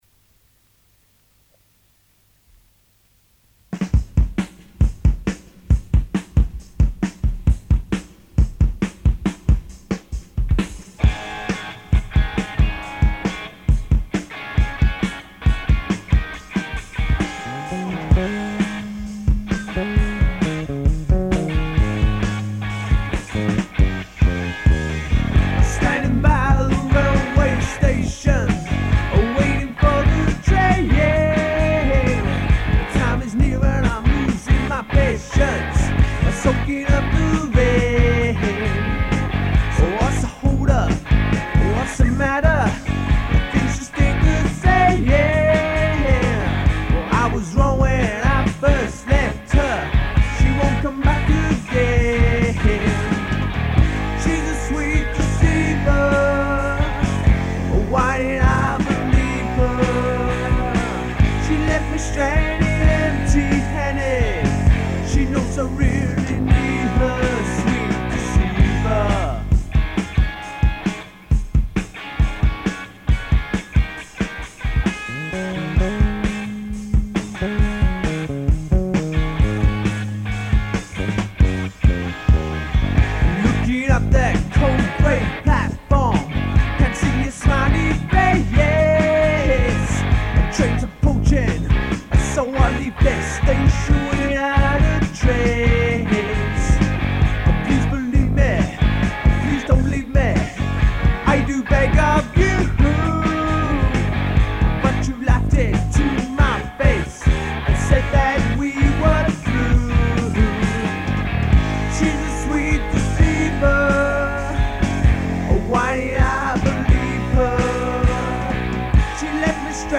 We set up the drums in the cottage adjacent the studios.